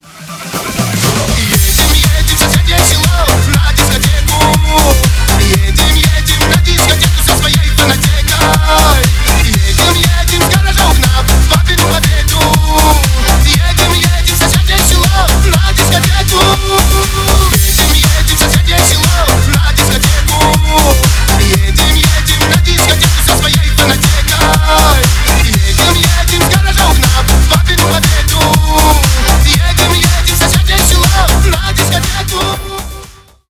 Ремикс # Поп Музыка
весёлые # кавказские